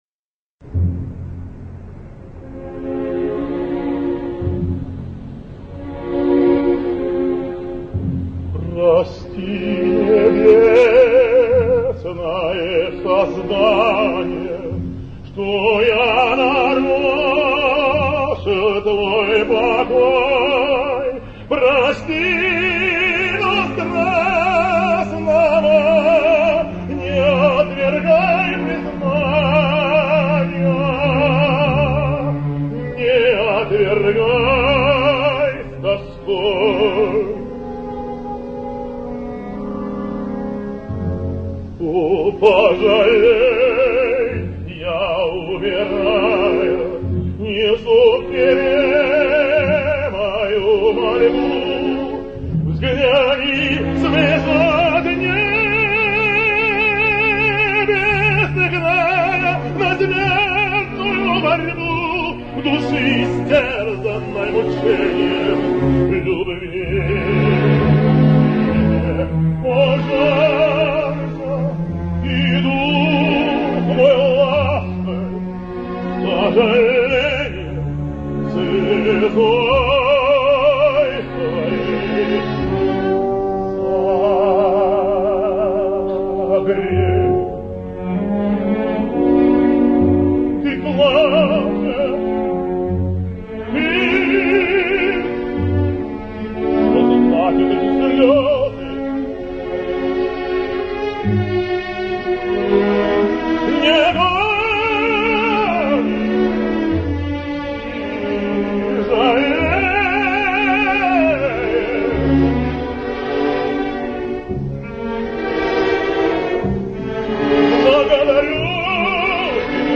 While Eastern Europe in general and Russia in particular have beyond doubt maintained quality in operatic singing for a much longer time than the rest of the world (and in some cases, they've maintained it even up to our days), it's the proof that there is no such thing as a Promised Land of opera, not even in Russia, if a positively horrible shouter and pusher like Osipov could become of the country's most famous tenors...
Vjacheslav Osipov singsPikovaja dama: